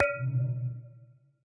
Minecraft Version Minecraft Version snapshot Latest Release | Latest Snapshot snapshot / assets / minecraft / sounds / block / end_portal / eyeplace2.ogg Compare With Compare With Latest Release | Latest Snapshot